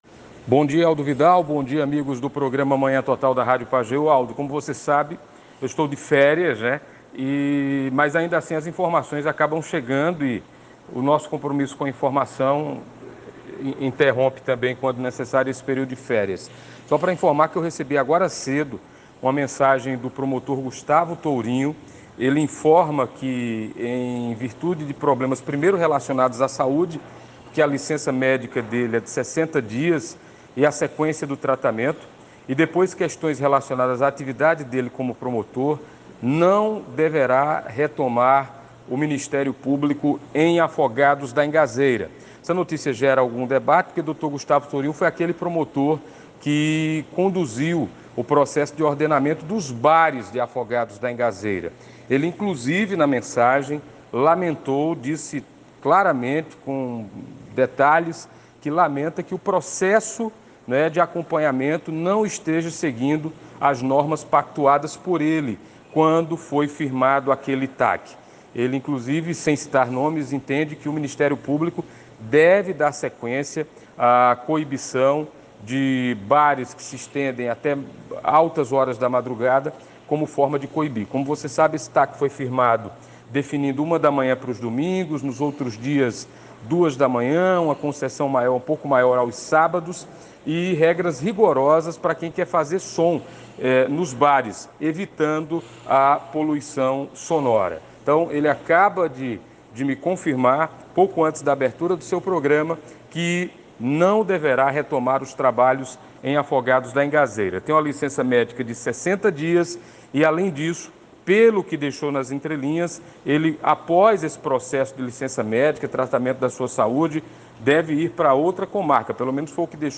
Gustavo-Tourinho.mp3